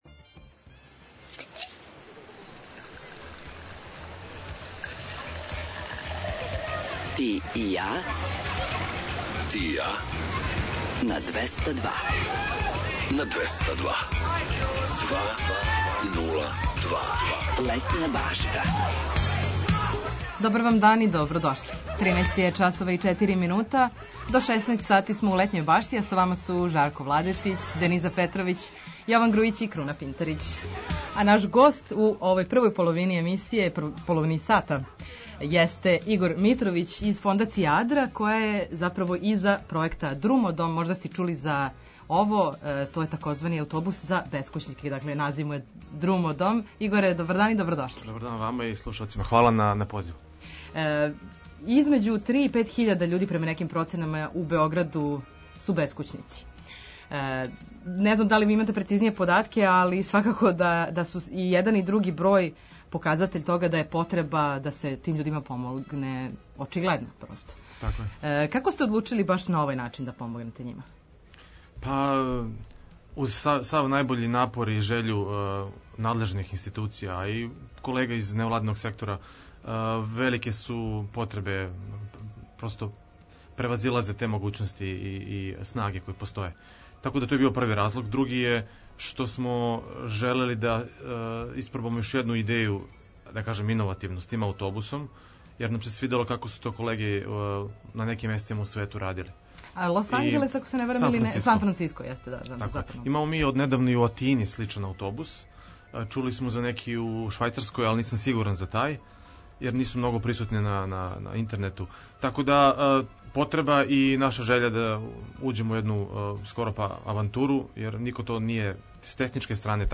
У наставку емисије очекује вас више музике, приче о песмама, важним албумима, рођенданима музичара, а завирићемо и у највеће хитове светских топ листа.
Предлажемо вам предстојеће догађаје широм Србије, свирке и концерте, пратимо сервисне информације важне за организовање дана, а наш репортер је на градским улицама, са актуелним причама.